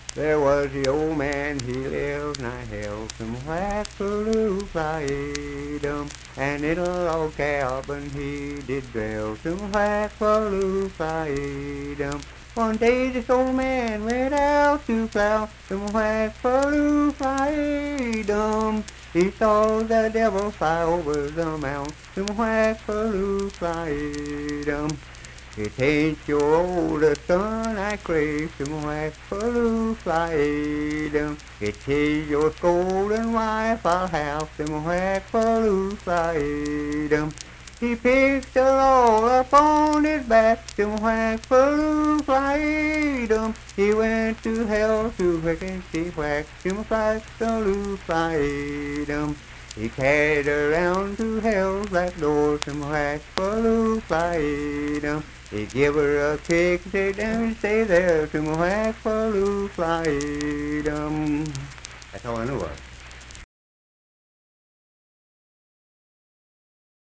Unaccompanied vocal and banjo music
Voice (sung)